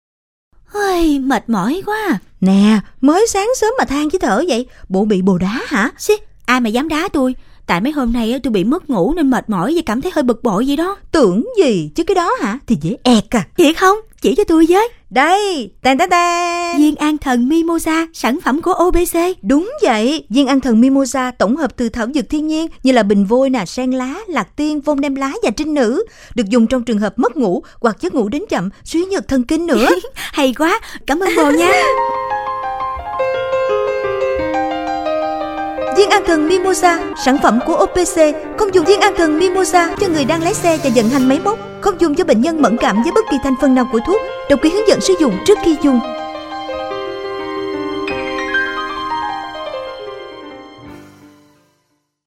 Trên đài phát thanh cho công chúng